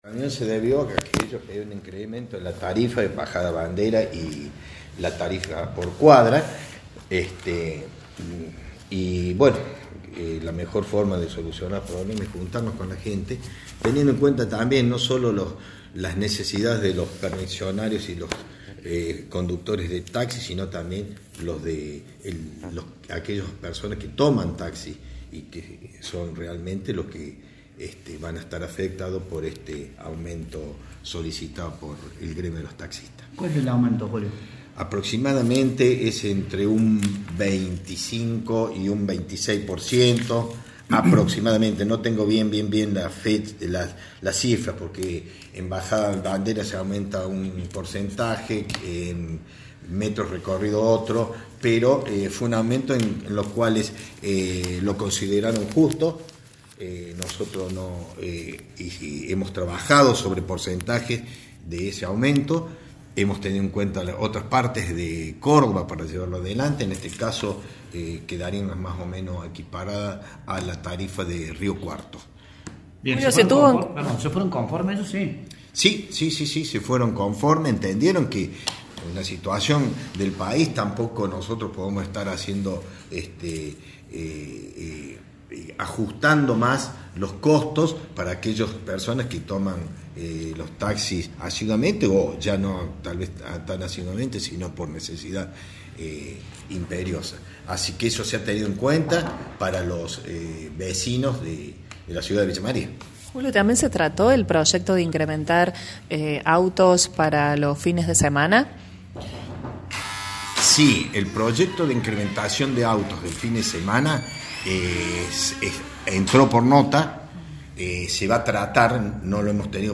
AUDIO – JULIO OYOLA, CONCEJAL
Escuchamos la palabra del Concejal que brindó a «La Mañana Informal».
Concejal-Julio-Oyola.mp3